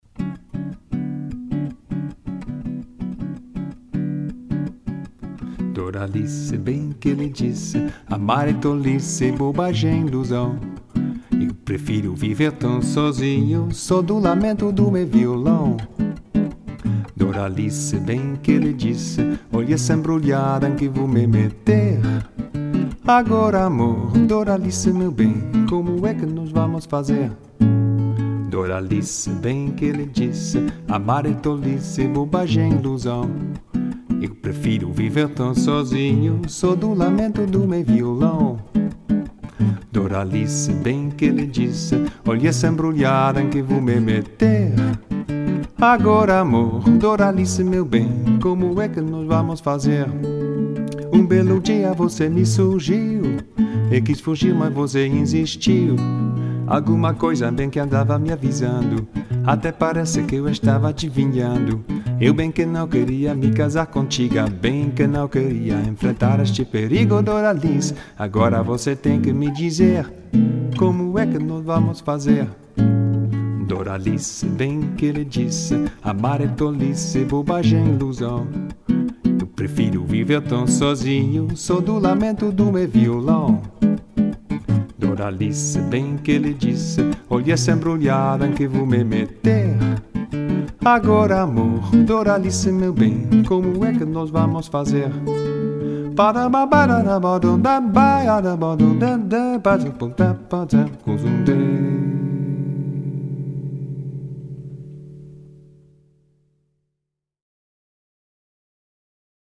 (vocal and guitar)